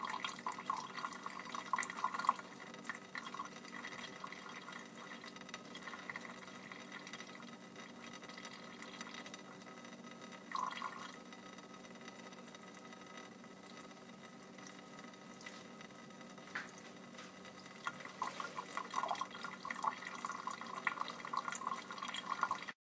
描述：从厨房水龙头滴下的水有混响
Tag: EFX